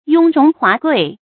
雍容华贵 yōng róng huá guì
雍容华贵发音